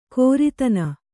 ♪ kōritana